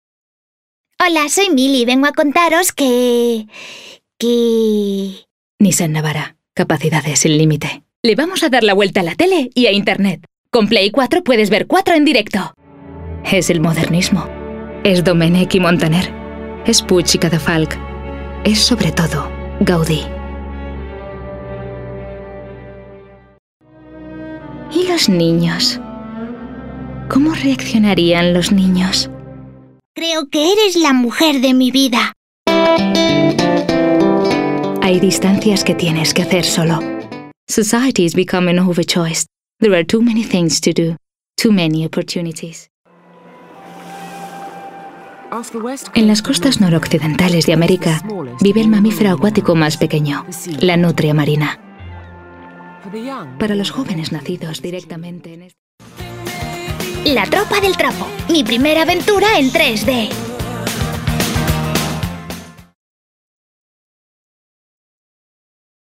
Voz media cálida con registros de tonos altos y timbrada para locuciones y narraciones.
Soprano.
kastilisch
Sprechprobe: Sonstiges (Muttersprache):